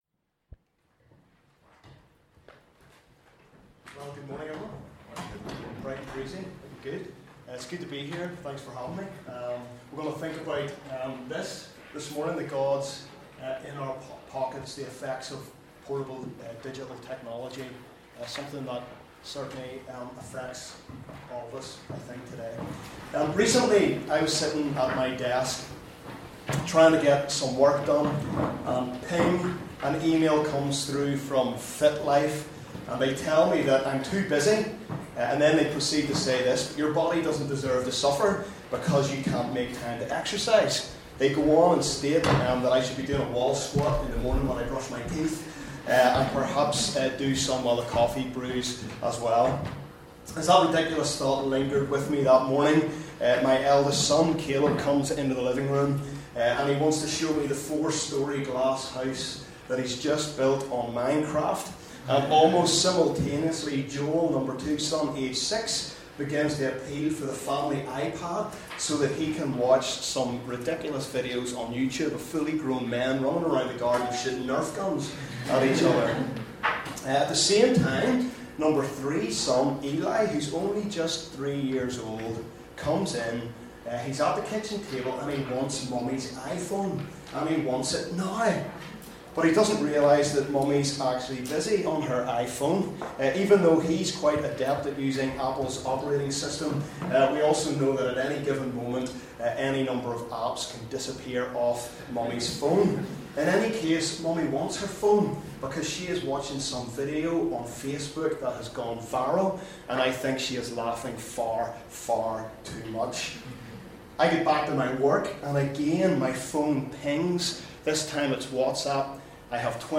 This recording is taken from the Transform Gathering which took place in the Cathedral Quarter, Belfast from 1st-2nd April 2016.